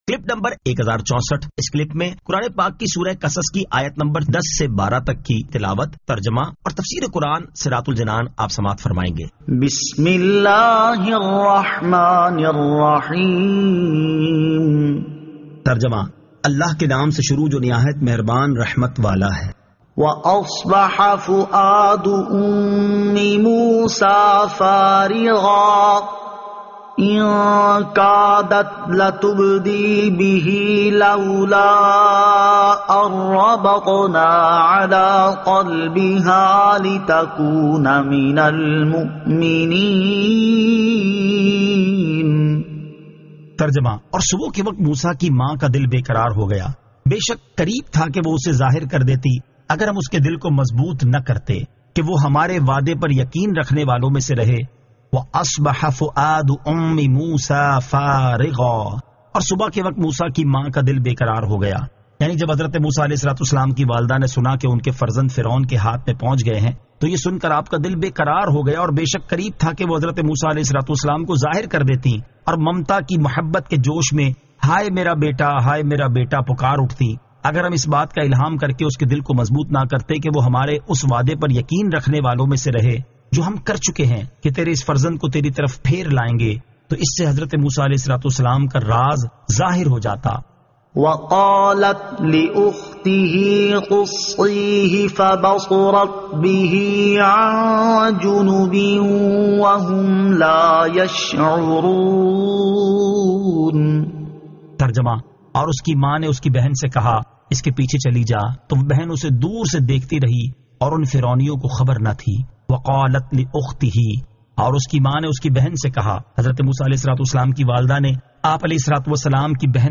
Surah Al-Qasas 10 To 12 Tilawat , Tarjama , Tafseer